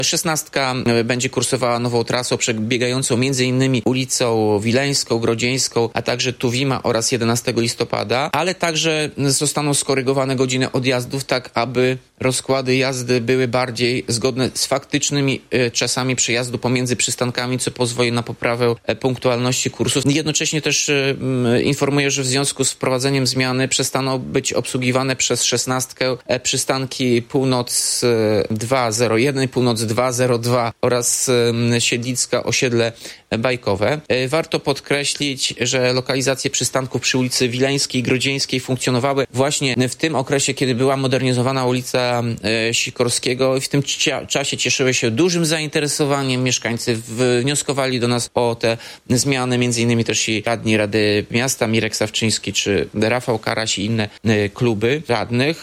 -To odpowiedź miejskiej spółki na potrzeby mieszkańców, mówi Radiu 5 Tomasz Andrukiewicz, prezydent miasta.